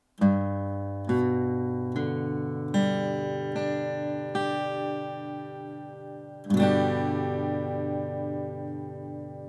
G-Dur (Offen)
G-Dur.mp3